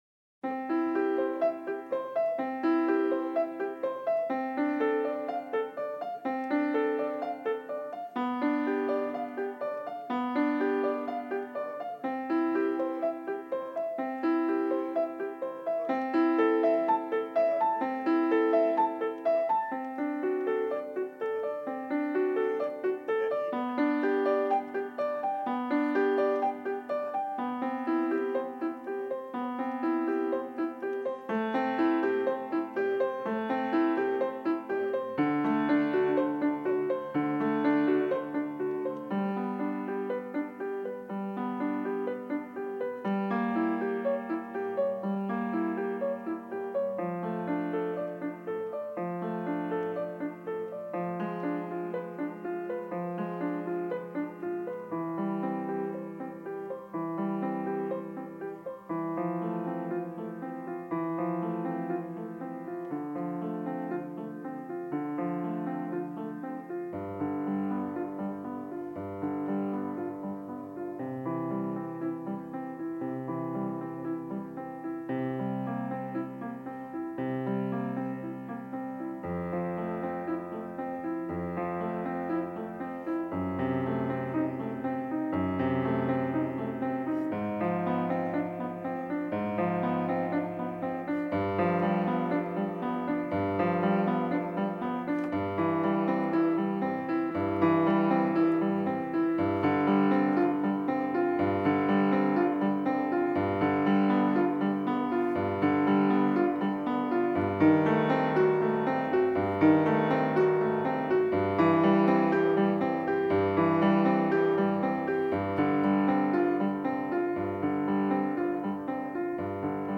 音樂類型：古典音樂